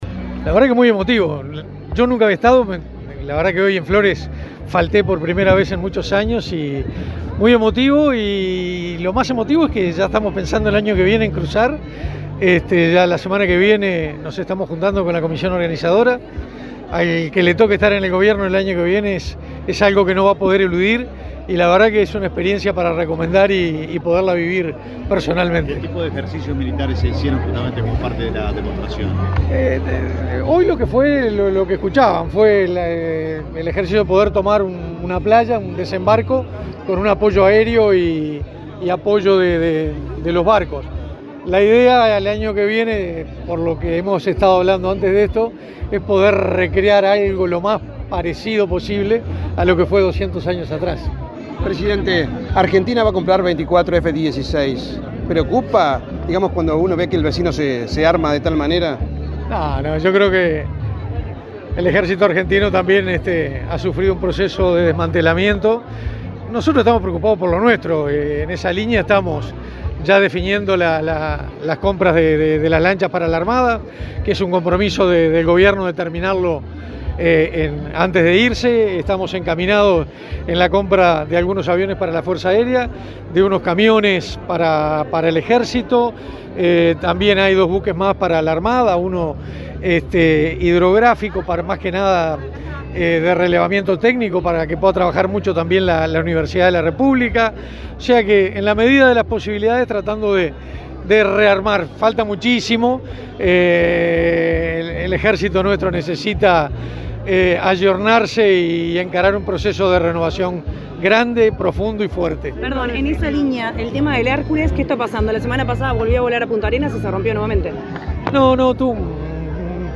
Declaraciones de prensa del ministro de Defensa Nacional, Armando Castaingdebat
El ministro de Defensa Nacional, Armando Castaingdebat, participó en el acto conmemorativo del 199.° aniversario del Desembarco de los Treinta y Tres
La ceremonia se realizó este viernes 19 en la playa de la Agraciada, departamento de Soriano.